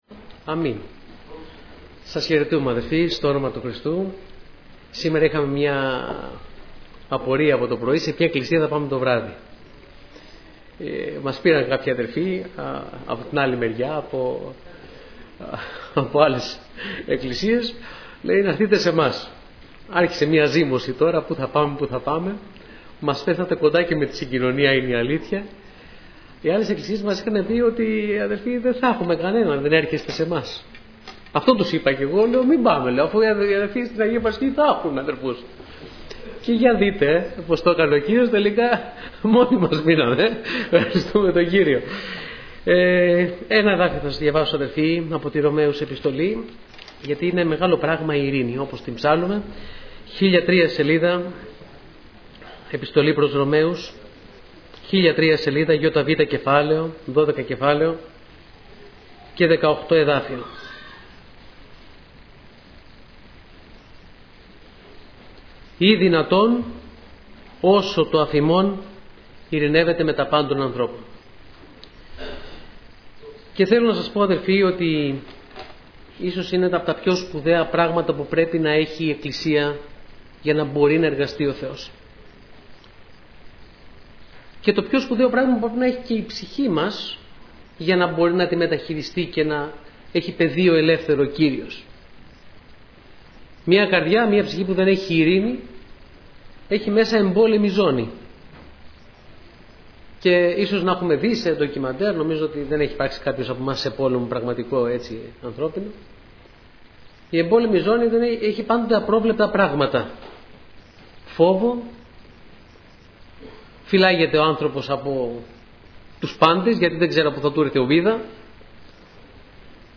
Διάφοροι Ομιλητές Λεπτομέρειες Σειρά: Κηρύγματα Ημερομηνία